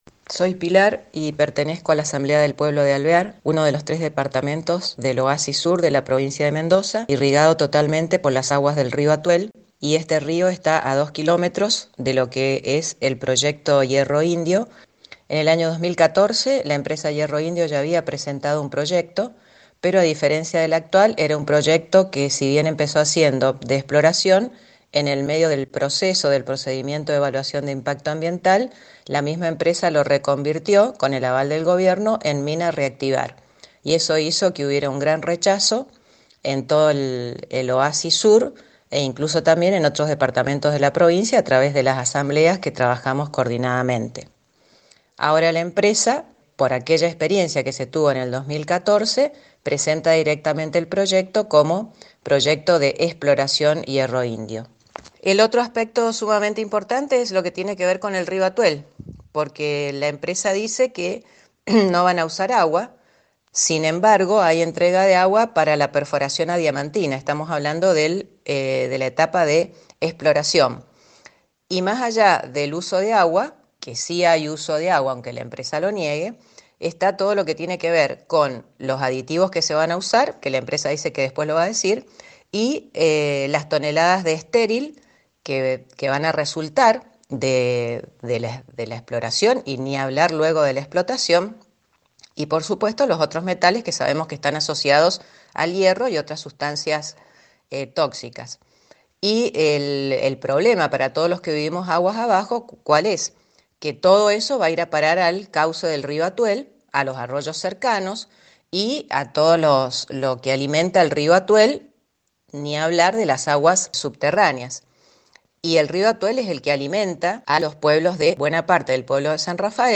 Testimonio de ello han dado dos de esos vecinos en contacto con el programa NO a la Mina Radio hace unos días.